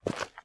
High Quality Footsteps / Dirt
STEPS Dirt, Walk 16.wav